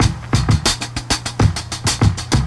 • 130 Bpm Breakbeat C# Key.wav
Free drum beat - kick tuned to the C# note. Loudest frequency: 1765Hz
130-bpm-breakbeat-c-sharp-key-mK2.wav